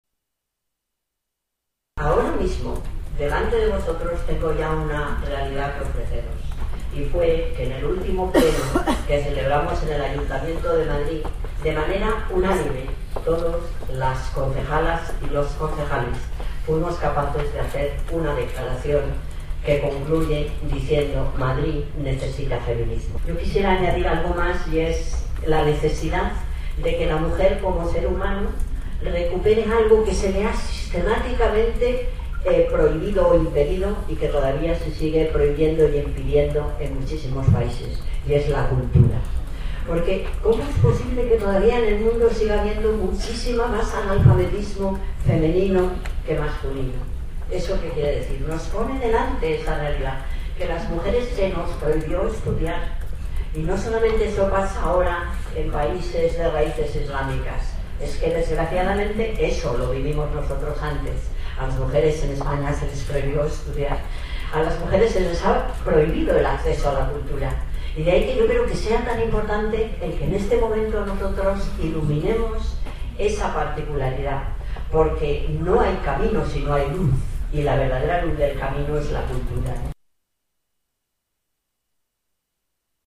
Carmena preside el acto de conmemoración del Día Internacional de las Mujeres.
Nueva ventana:Intervención de Manuela Carmena en la entrega del premio Clara Campoamor a Celia Amorós